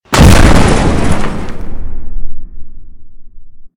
Explosion1.ogg